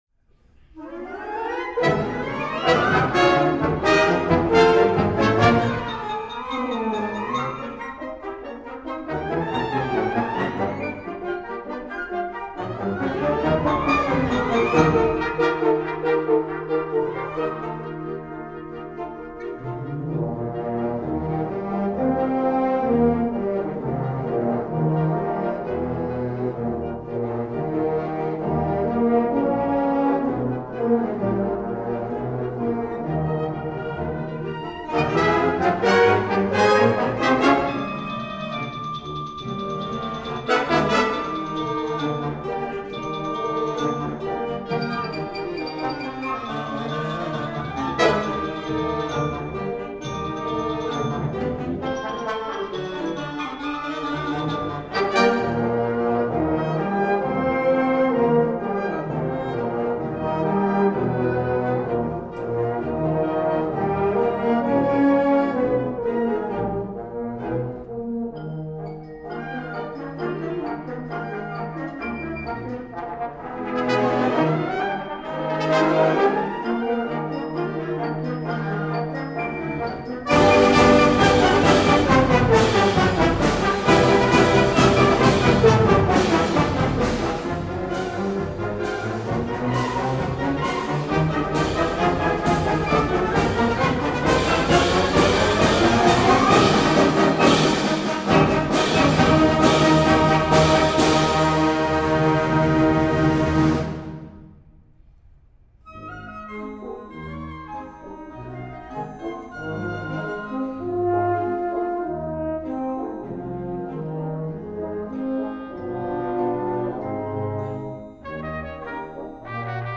編成：吹奏楽
Tuba
Xylophone
Glockenspiel